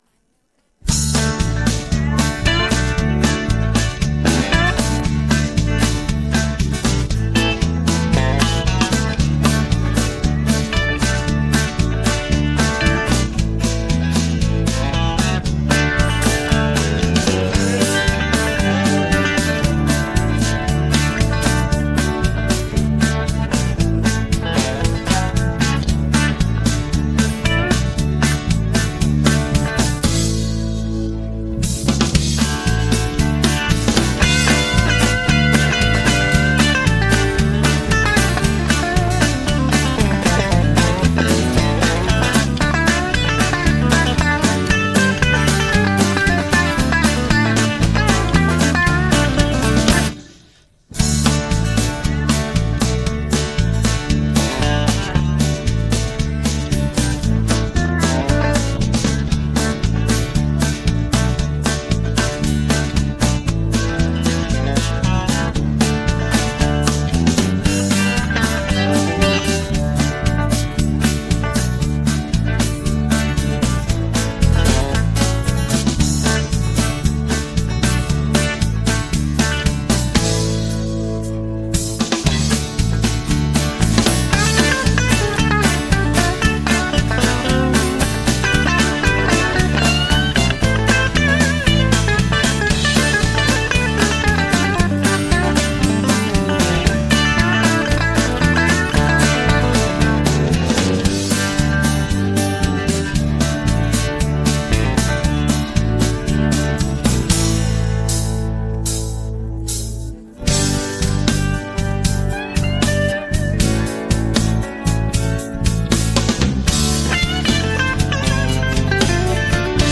Übungsaufnahmen - Big Fat Man
Big Fat Man (Playback)